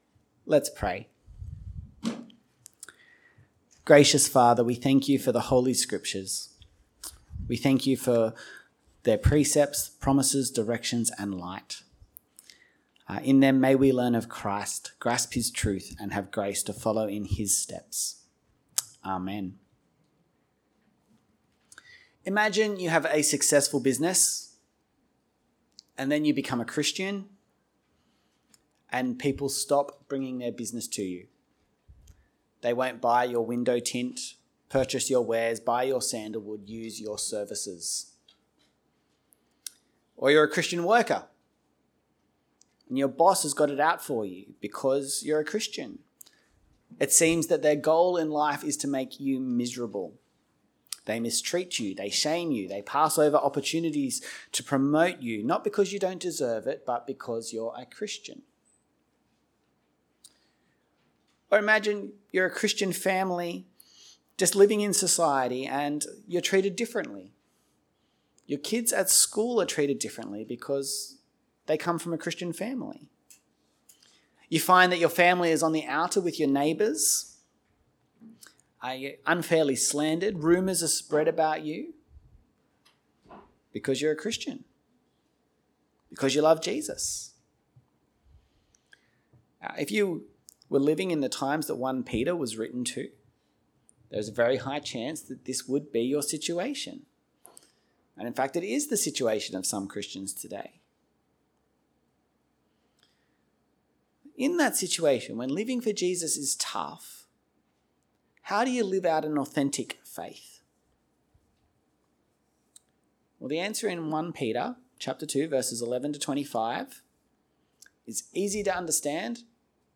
A message from the series "One Off Sermons."